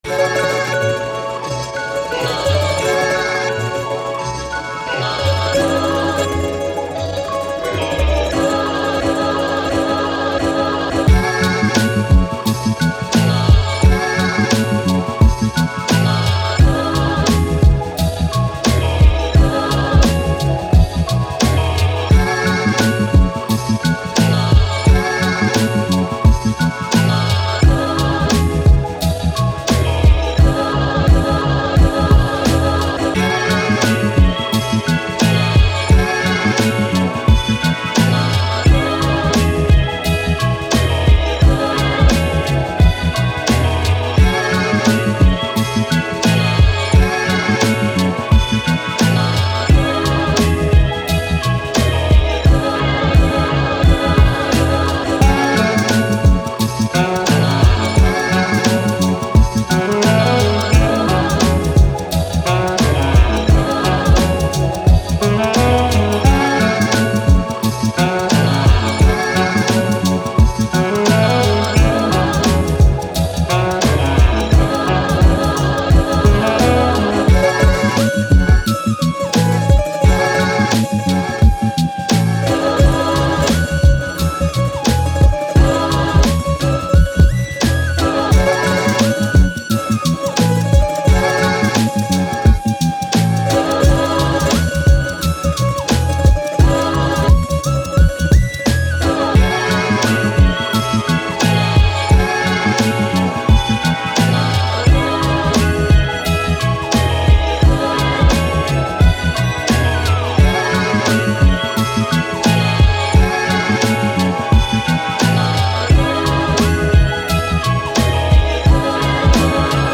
Hip Hop, Boom Bap, Action